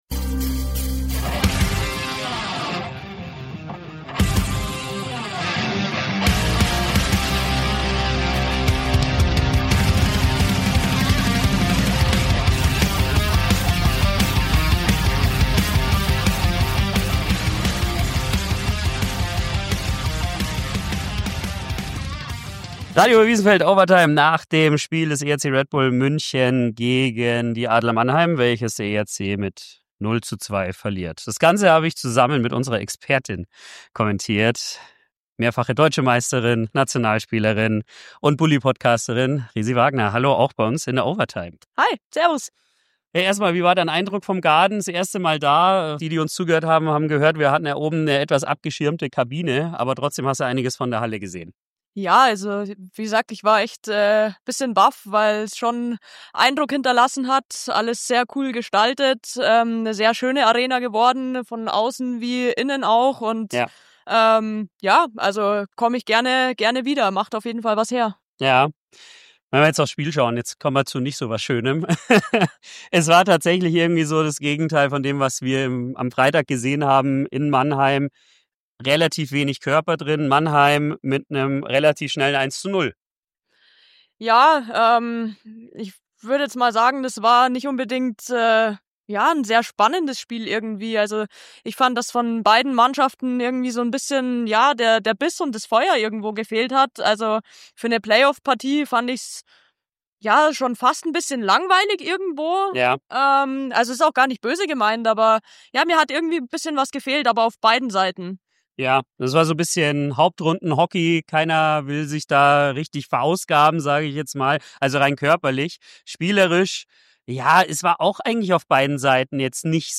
Special guest am Mikro!